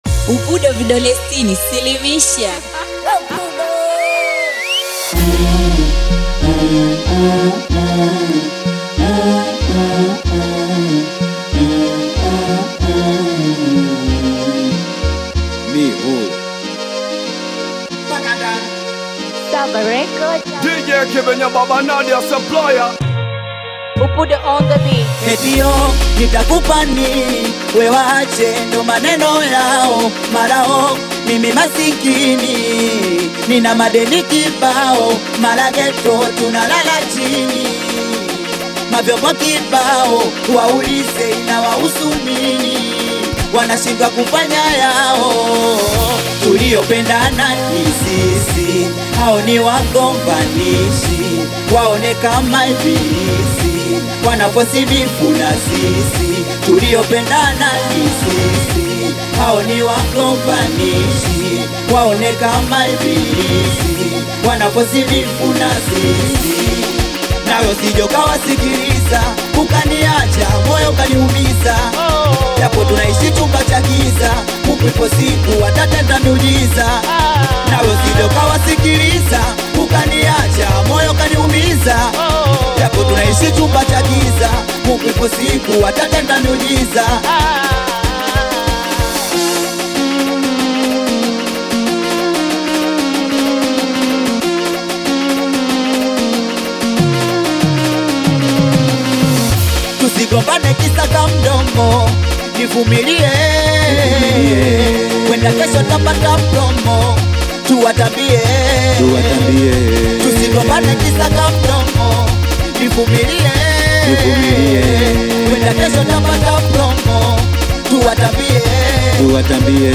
If you’re a fan of cutting-edge hip-hop and R&B